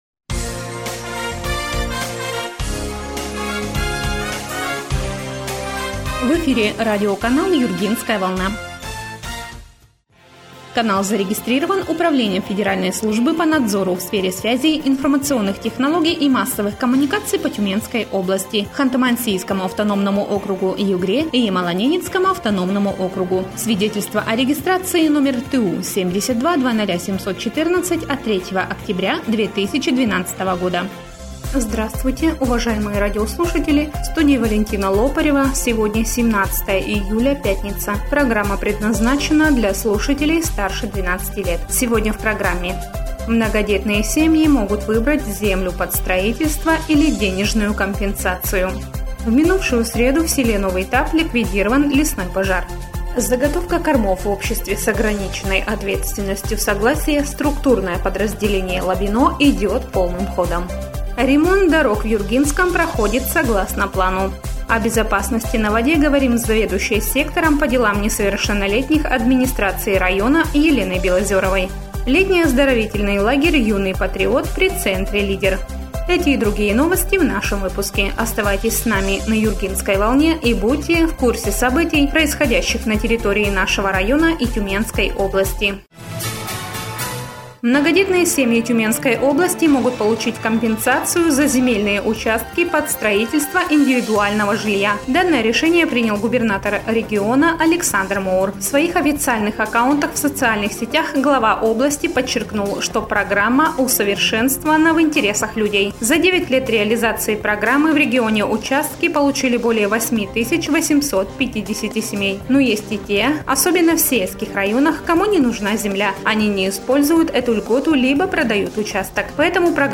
Эфир радиопрограммы "Юргинская волна" от 17.07.2020